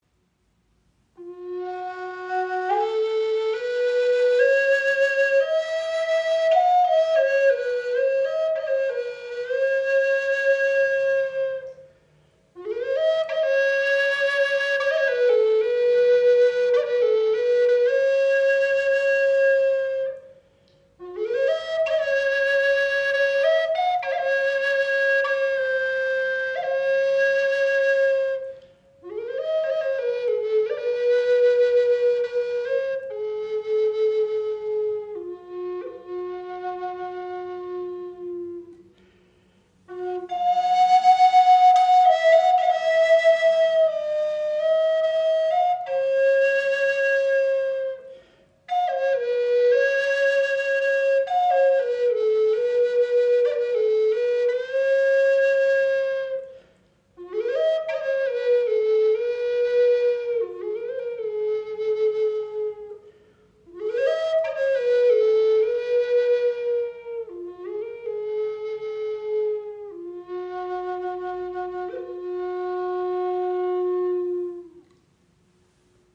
Gebetsflöte High Spirit in F# - Golden Eagle im Raven-Spirit WebShop • Raven Spirit
Klangbeispiel
Gebetsflöte in F# Aus Walnuss 57 cm lang, 6 Grifflöcher Diese High Spirit Flöte in F# mit einem Adler Windblock wurde aus Walnussholz erschaffen und mit biologischen Öl versiegelt.